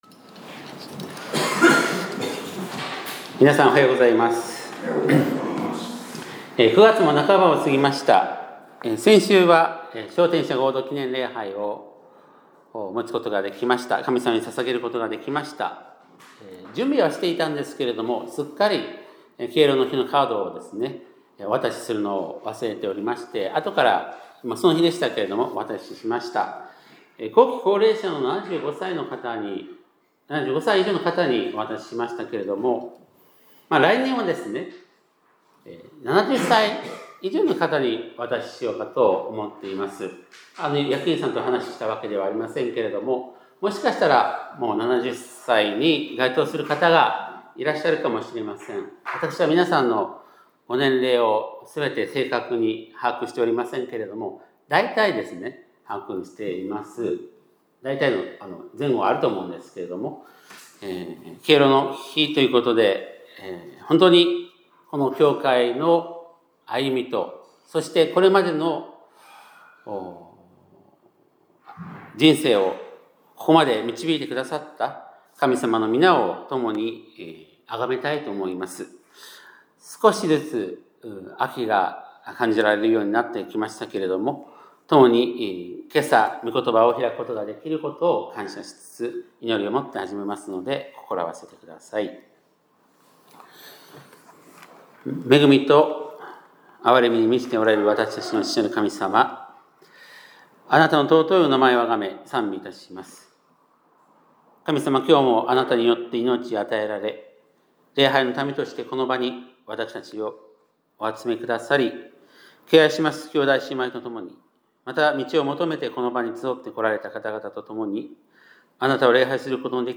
2025年９月21日（日）礼拝メッセージ